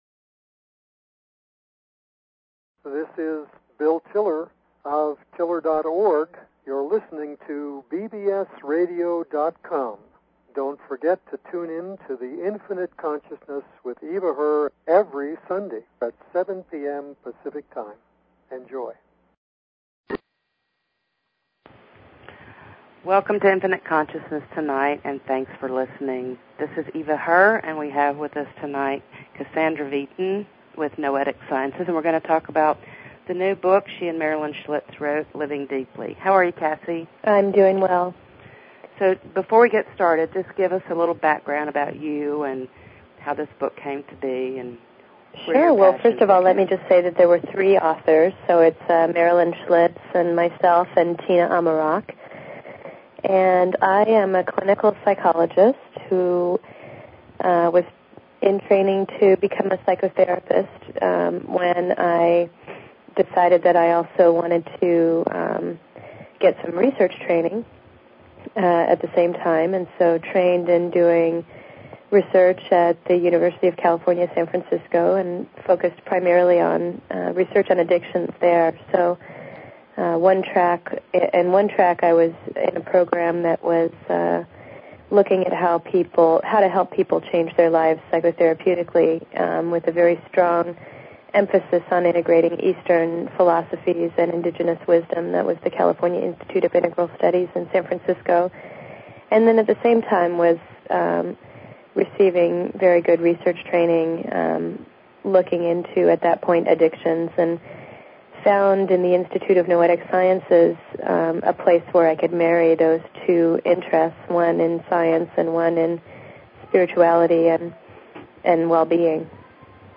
Talk Show Episode, Audio Podcast, The_Infinite_Consciousness and Courtesy of BBS Radio on , show guests , about , categorized as